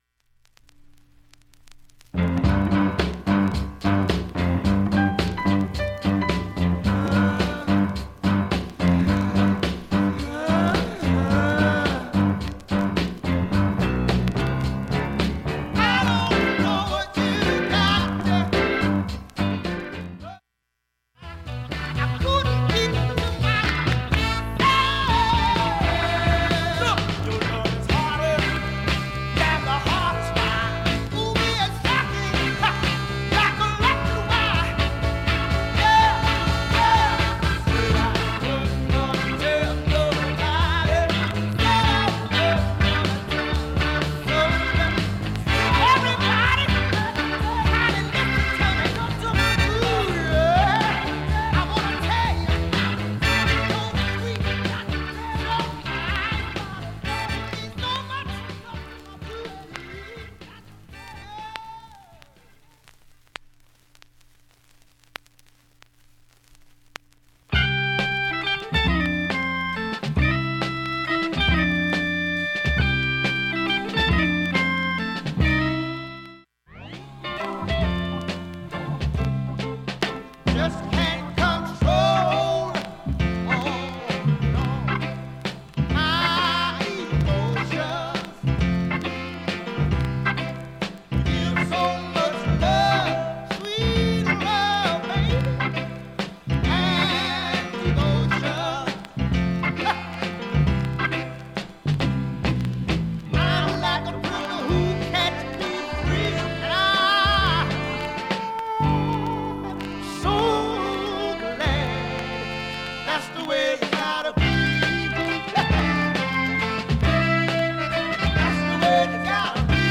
かすかなチリ音が無音部で出ますが
音質良好全曲試聴済み。
A-1始めにかすかなプツが４回出ます。
◆ＵＳＡ盤オリジナル Mono